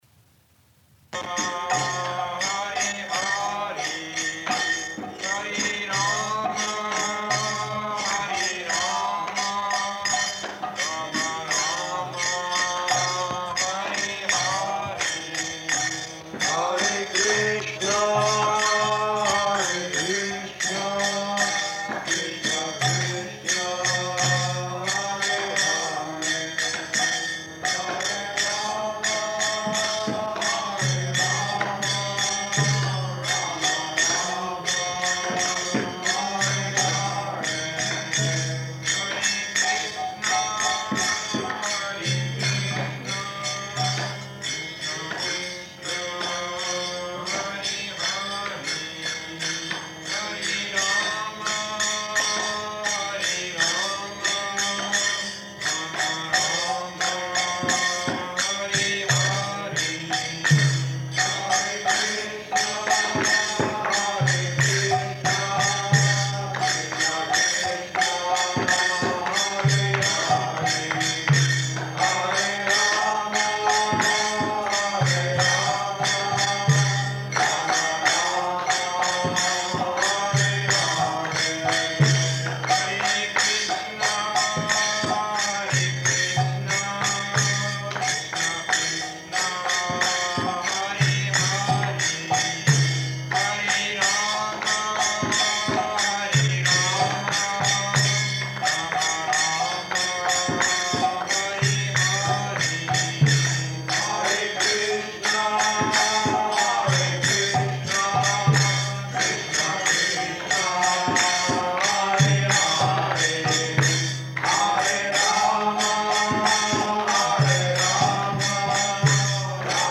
Śrīmad-Bhāgavatam 1.2.6 --:-- --:-- Type: Srimad-Bhagavatam Dated: August 3rd 1968 Location: Montreal Audio file: 680803SB-MONTREAL.mp3 Prabhupāda: [ kīrtana ] [ prema-dvani prayers] Thank you very much.